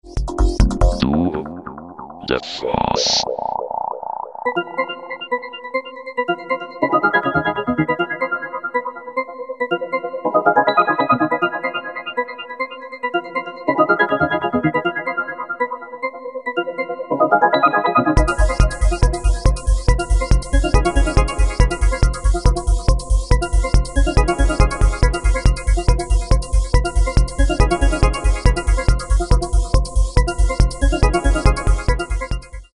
techno-influenced neo-industrial synthpop sound